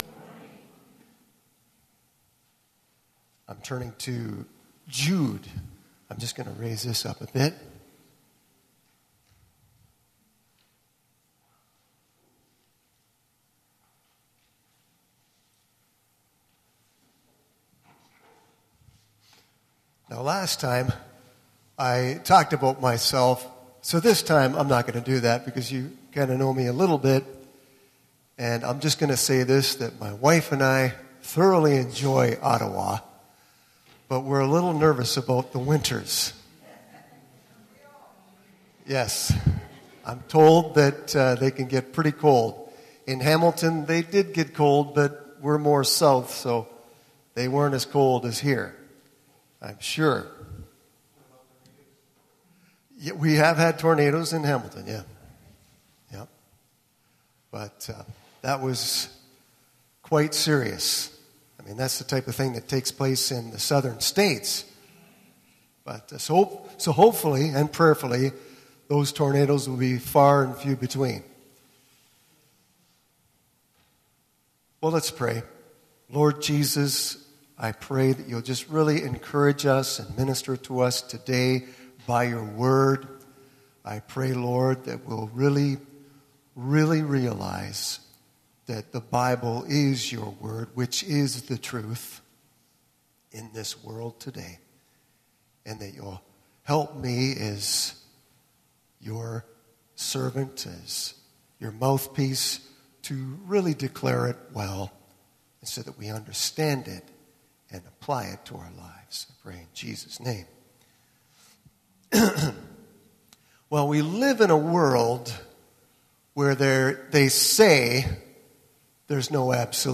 This sermon is based on Jude 3-4, 17-25